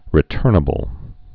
(rĭ-tûrnə-bəl)